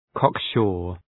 Προφορά
{‘kɒk,ʃʋr}
cocksure.mp3